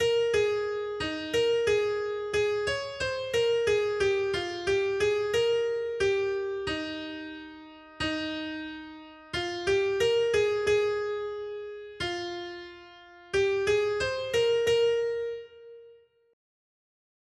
Noty Štítky, zpěvníky ol46.pdf responsoriální žalm Žaltář (Olejník) 46 Ž 84, 1-13 Skrýt akordy R: Šťastní jsou, Pane, kdo přebývají ve tvém svatém chrámě. 1.